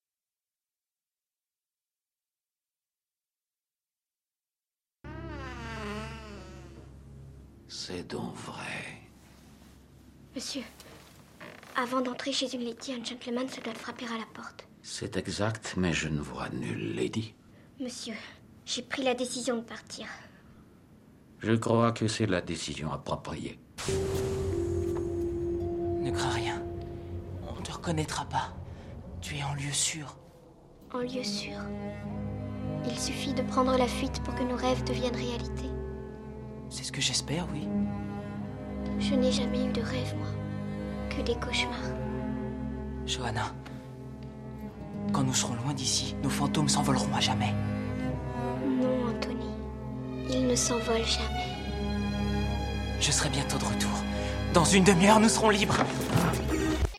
démo voix fiction